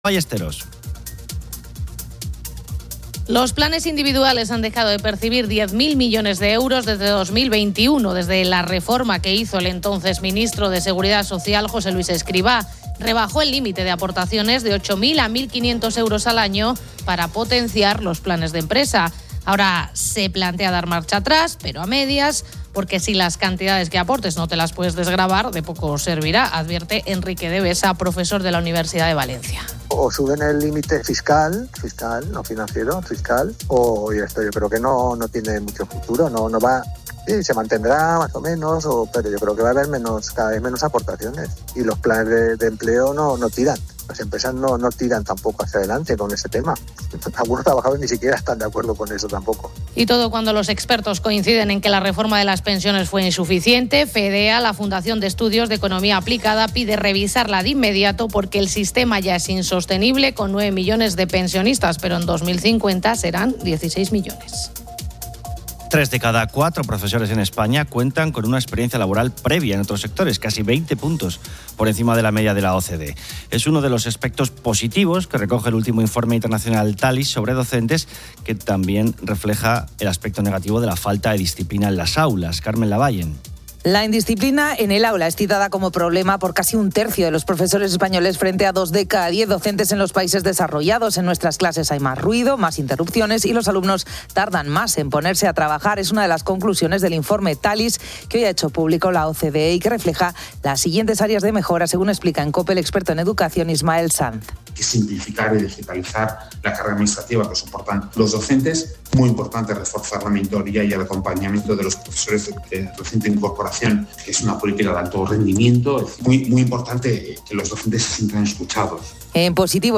Canta en directo.